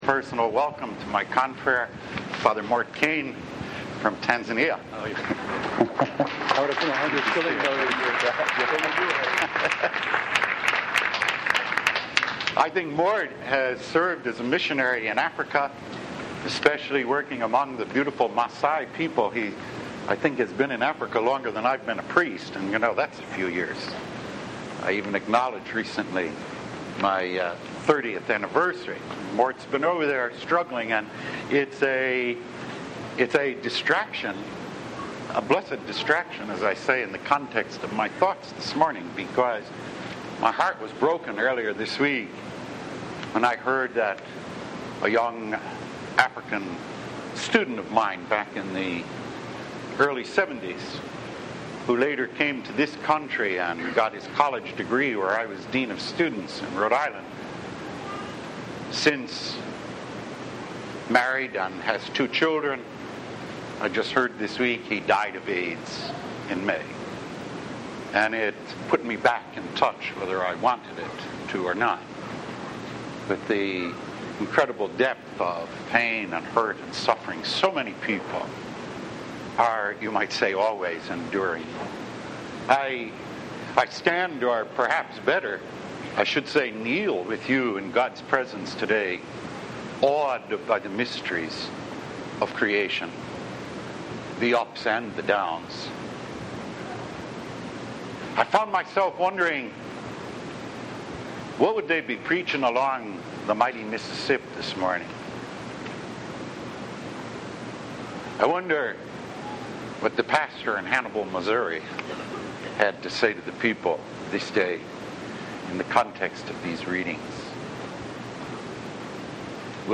15th Sunday of Ordinary Time « Weekly Homilies
Originally delivered on July 11, 1993